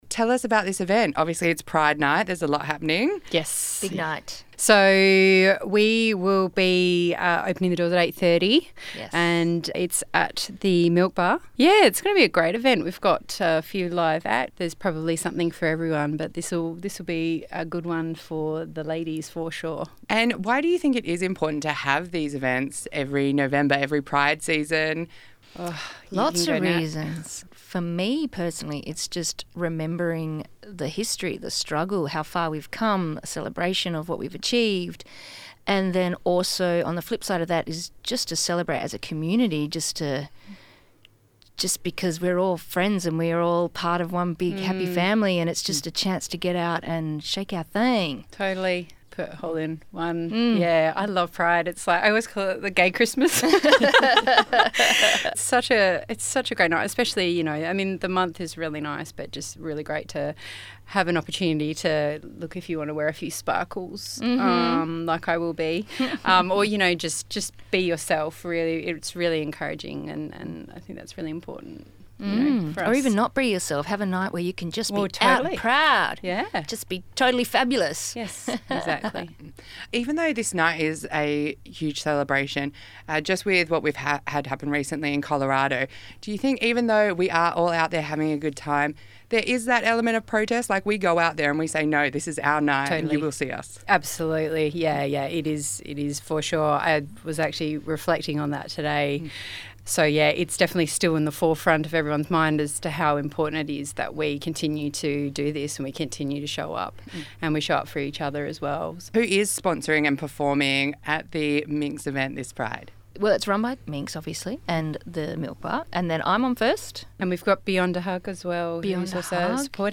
Minx-pride-interview-v1_01.mp3